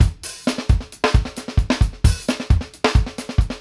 FUNK+GHOST-R.wav